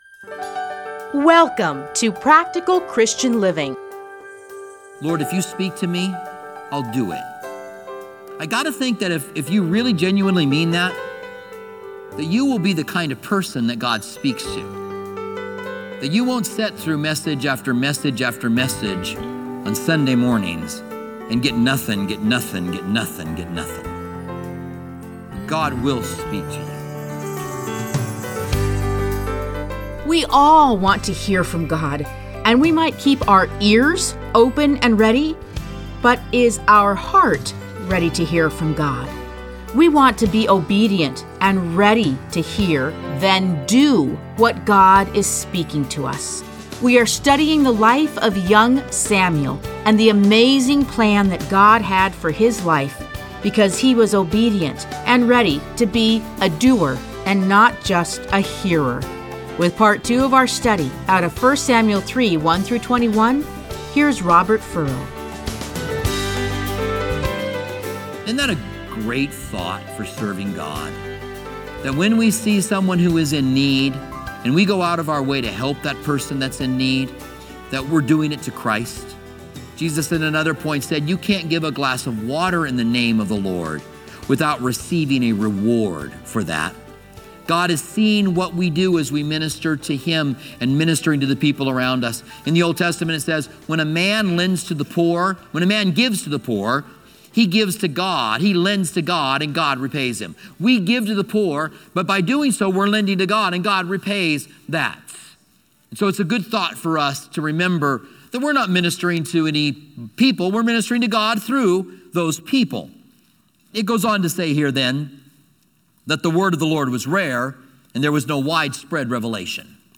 Listen to a teaching from 1 Samuel 3:1-21.